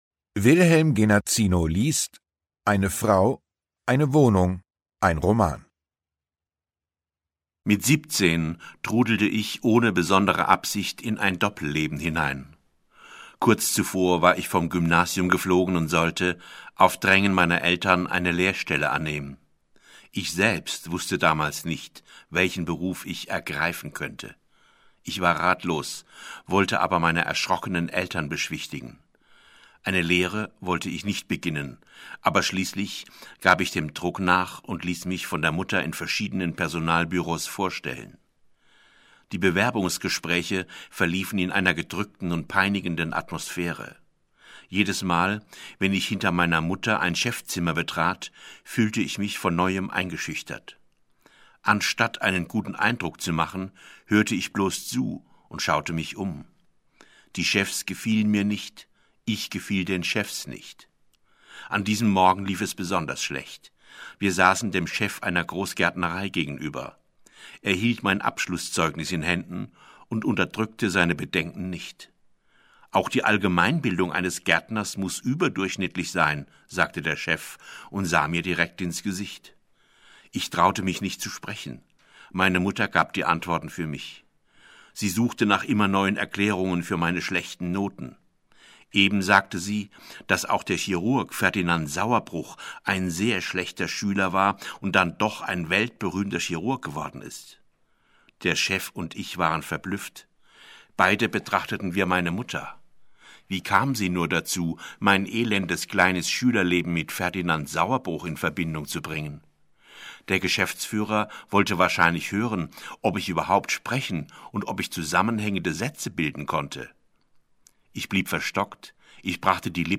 Ungekürzte Autorenlesung (1 mp3-CD)
Wilhelm Genazino (Sprecher)